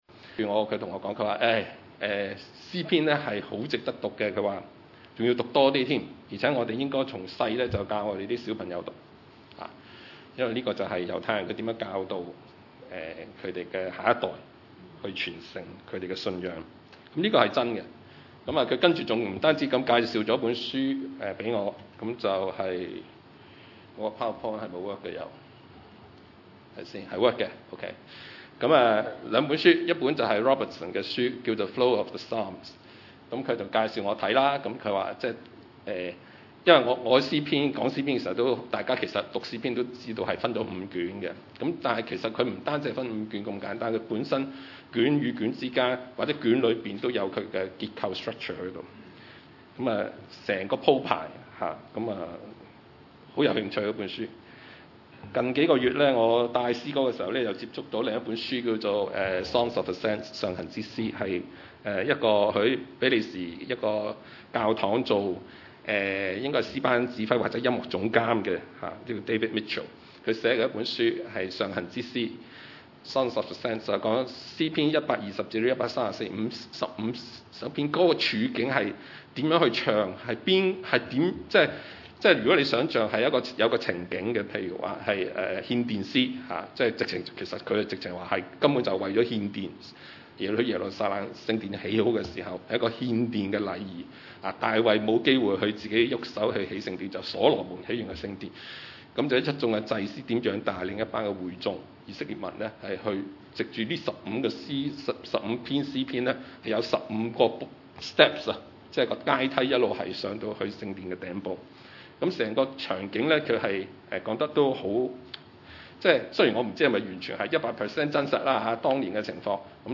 經文: 詩篇 139: 1-18節 崇拜類別: 主日午堂崇拜 1 耶和華啊，祢已經鑒察我、認識我。